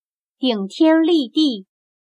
顶天立地/Dǐngtiānlìdì/Cabeza al cielo, pies al suelo. Describe la imagen de un espíritu majestuoso y alto, extraordinario.